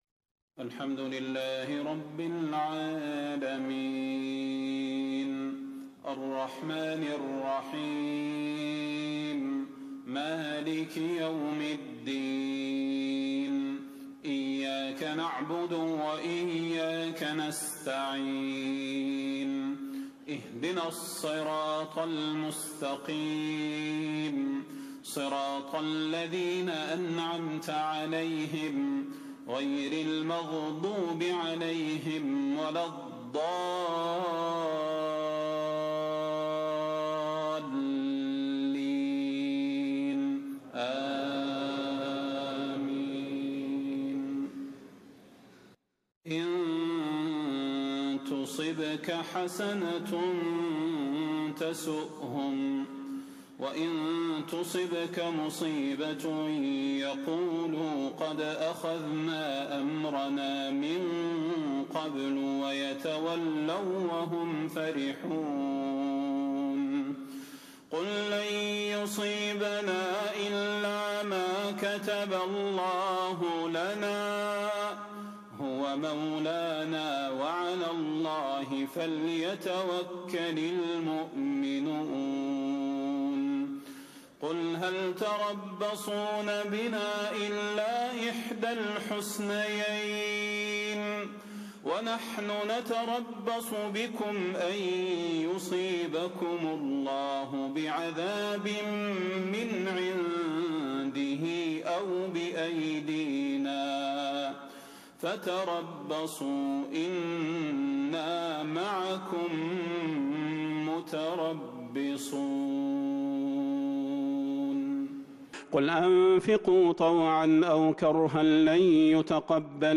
صلاة المغرب ٢٢ ذو الحجة ١٤٣٦هـ من سورة التوبة 50-55 > 1436 🕌 > الفروض - تلاوات الحرمين